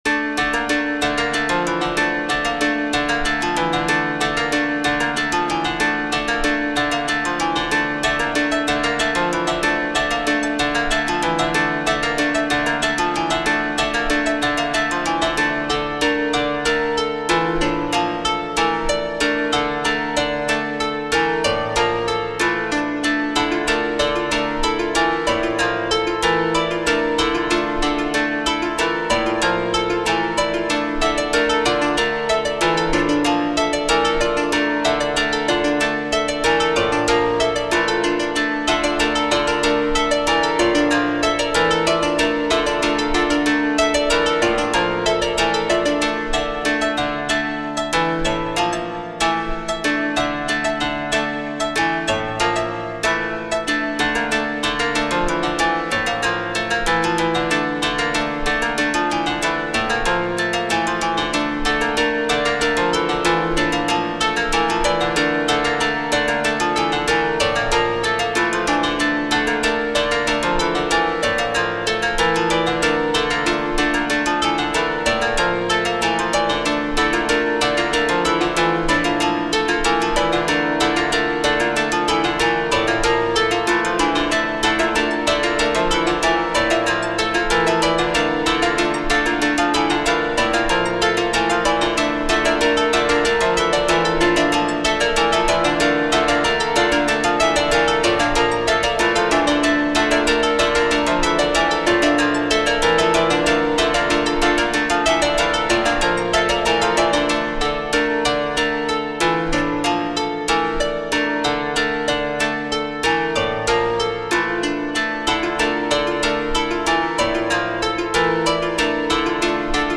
ホラー/怖い 不思議/ミステリアス 不気味/奇妙 和風 変わり種 寂しい/悲しい 怪しい 暗い 琴 コメント